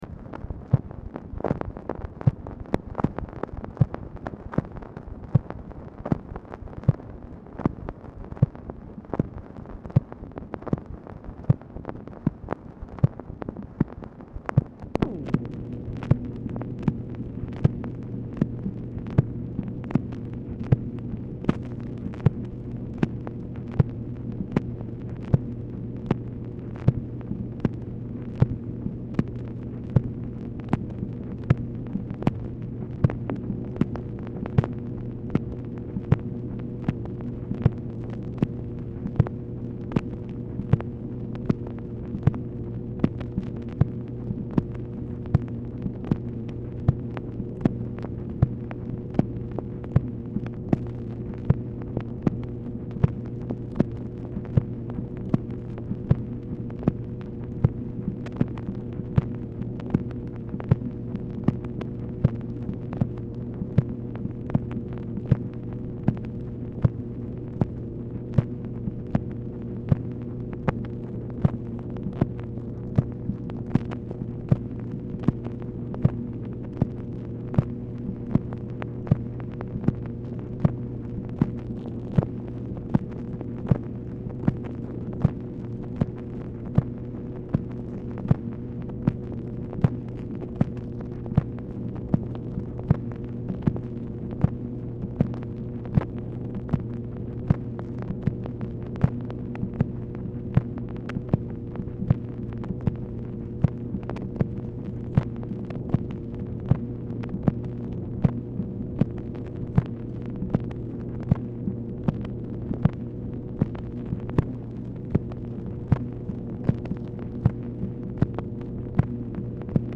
Telephone conversation # 9316, sound recording, MACHINE NOISE, 12/11/1965, time unknown · Discover Production